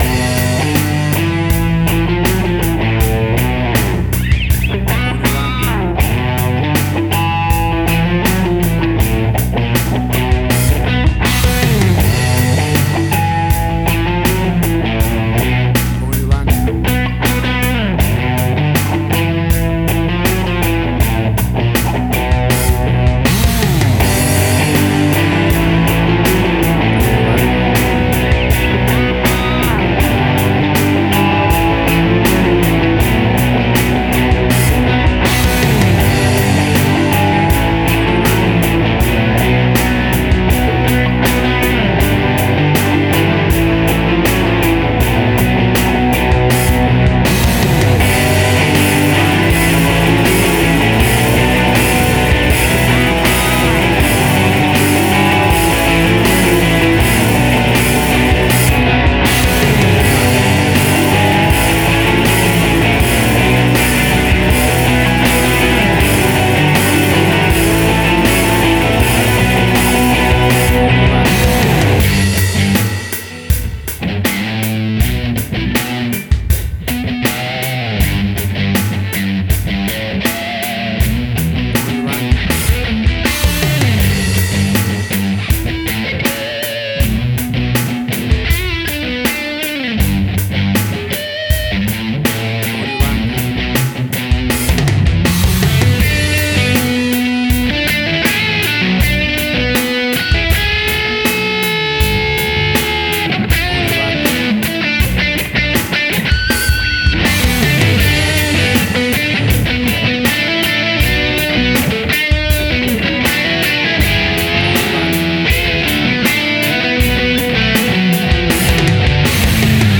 Indie Rock sounds.
Tempo (BPM): 80